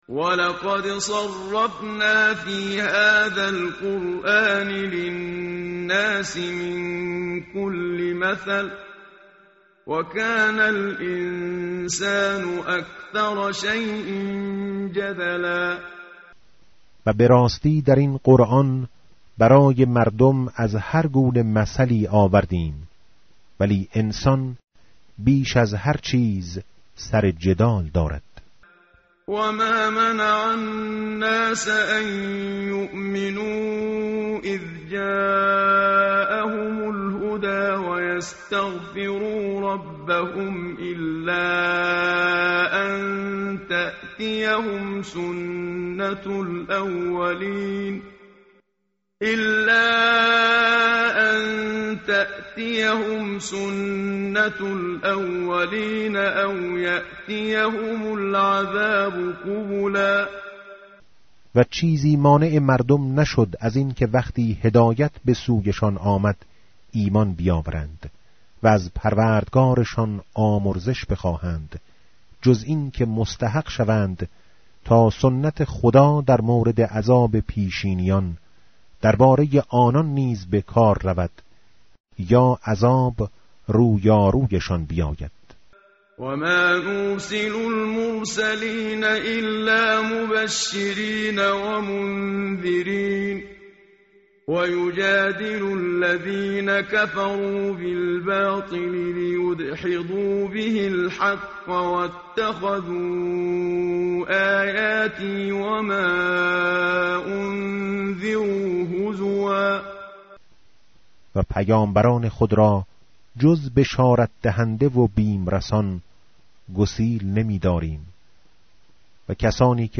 tartil_menshavi va tarjome_Page_300.mp3